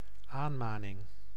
Ääntäminen
IPA: [ra.pɛl]